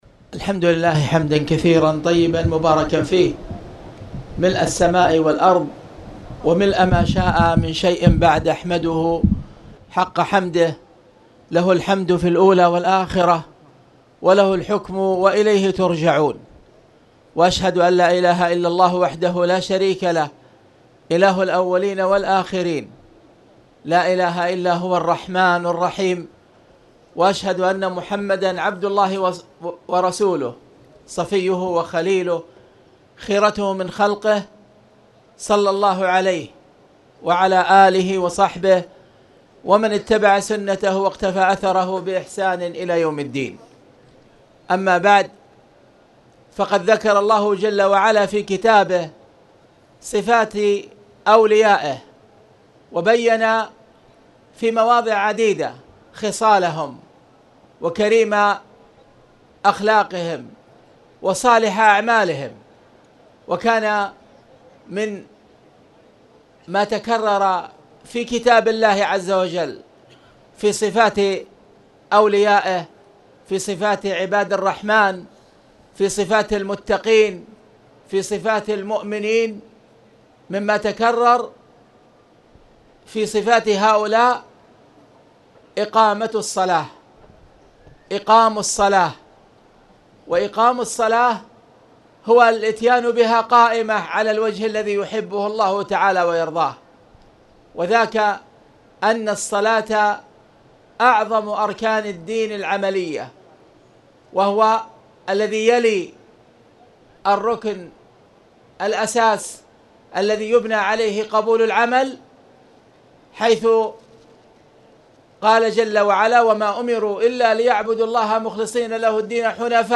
تاريخ النشر ١ ربيع الثاني ١٤٣٨ هـ المكان: المسجد الحرام الشيخ